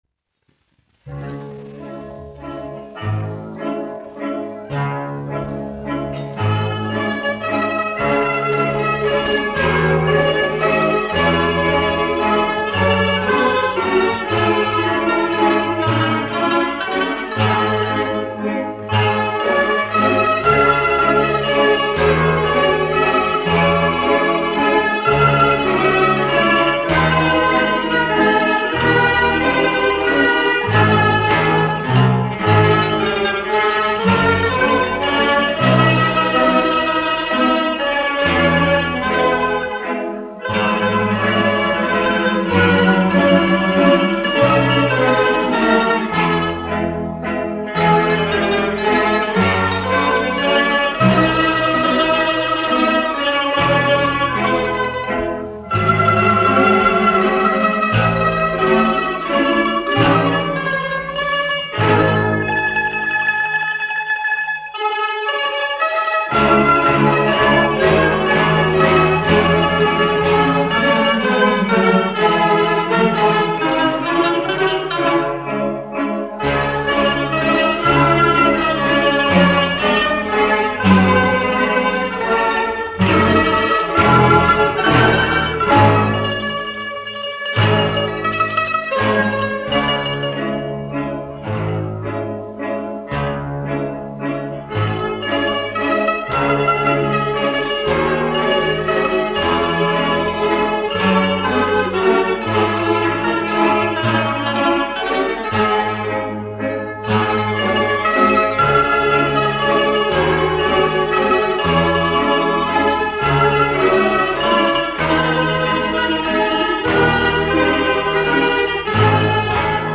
1 skpl. : analogs, 78 apgr/min, mono ; 25 cm
Operas--Fragmenti, aranžēti
Rīgas pilsētas krievu ģimnāzijas balalaiku orķestris
Latvijas vēsturiskie šellaka skaņuplašu ieraksti (Kolekcija)